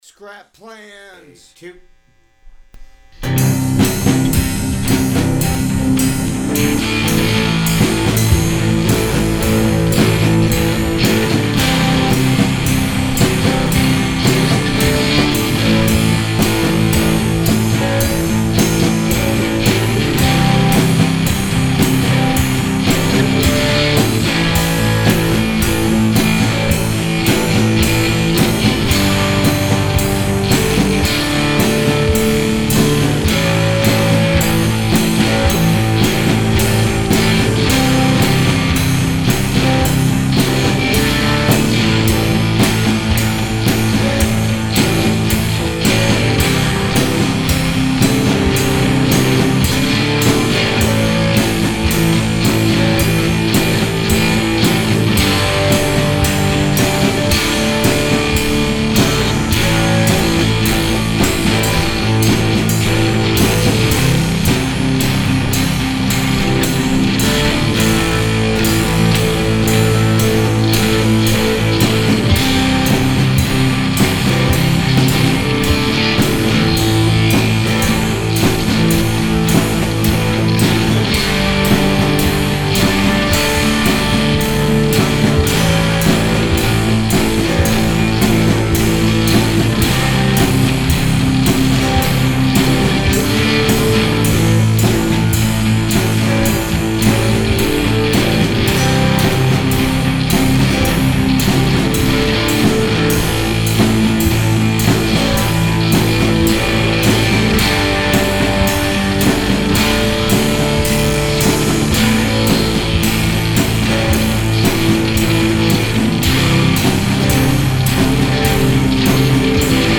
punk rock